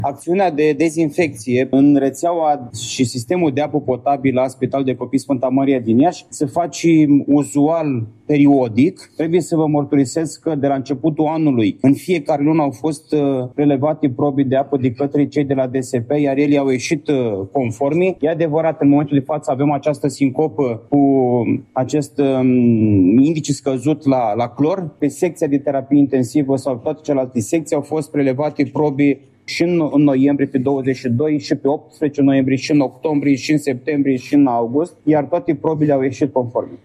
Situația de criză de la Spitalul de Copii „Sfânta Maria” din Iași a apărut în urma efectuării dezinfecției instalației de apă programate pentru luna noiembrie, a declarat președintele Consiliului Județean Iași, Costel lexe, instituție în subordinarea căreia se află unitatea medicală.